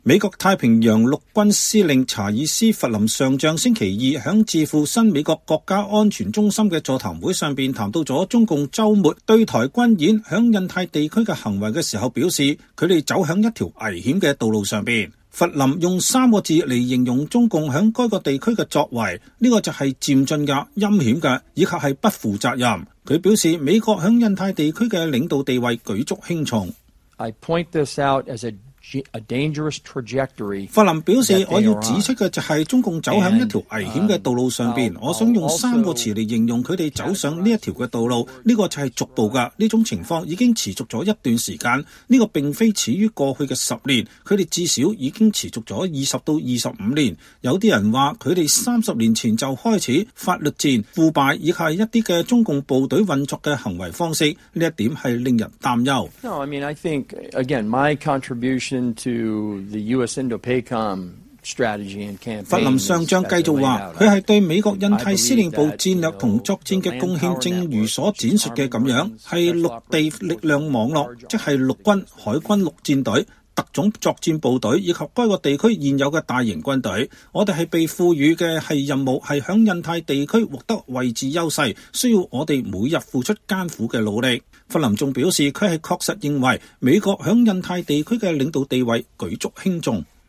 美國太平洋陸軍司令查爾斯·弗林上將週二在智庫新美國安全中心的座談會上談到中共週末對台軍演及在印太地區的行為時說，“他們走在一條危險的道路上。”